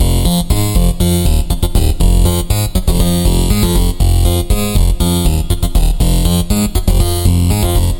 Tag: 120 bpm Electro Loops Synth Loops 1.35 MB wav Key : Unknown